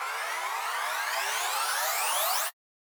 MB Trans FX (12).wav